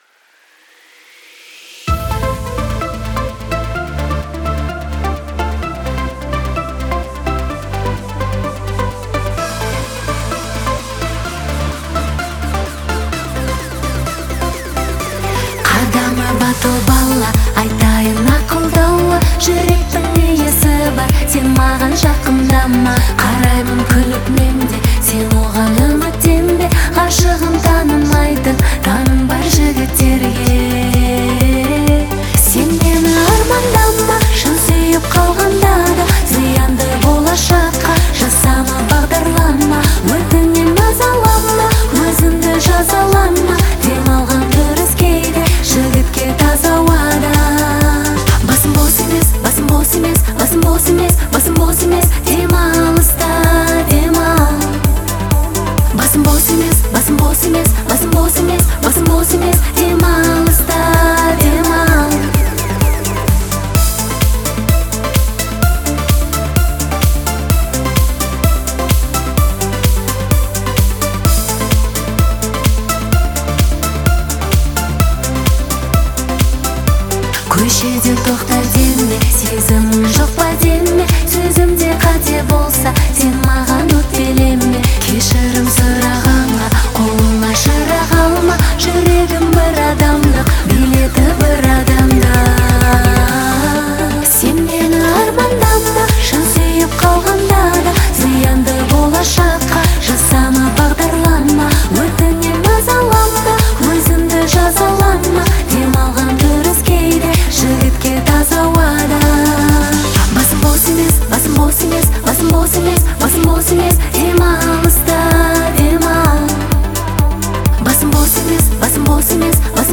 мощный и эмоциональный